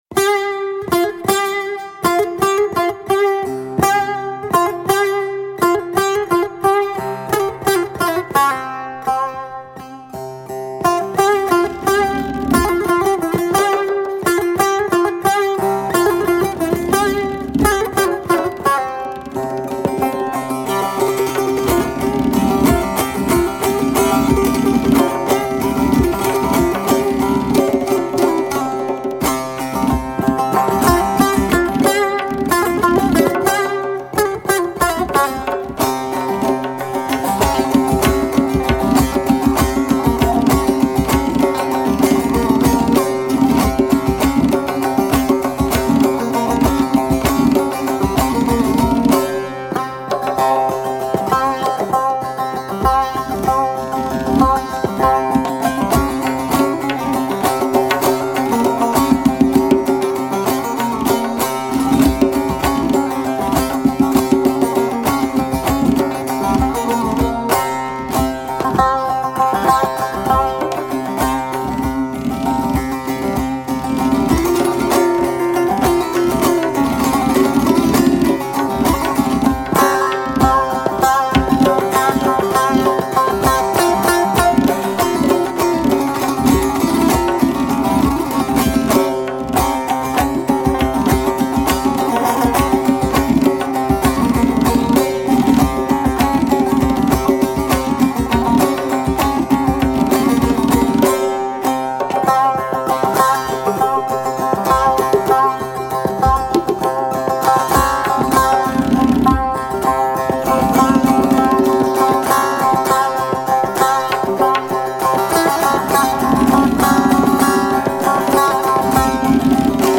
نوازنده تنبک